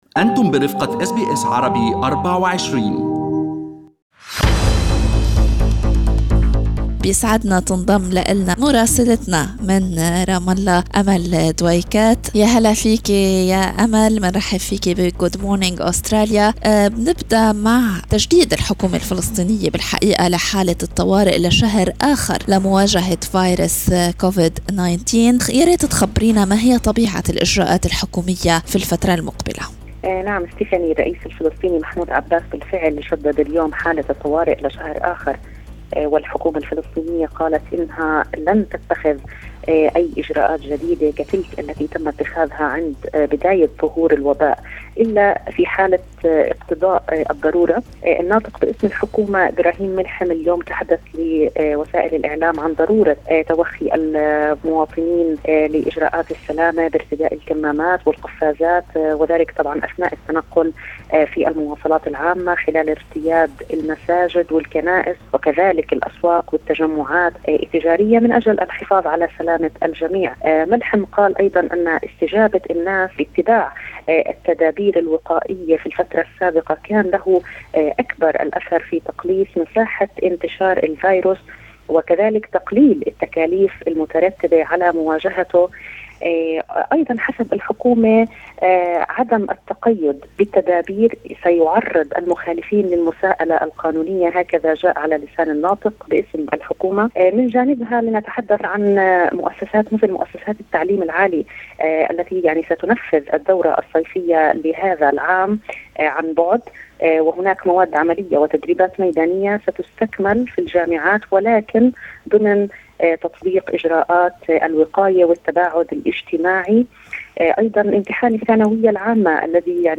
من مراسلينا: أخبار الأراضي الفلسطينية في أسبوع 04/06/2020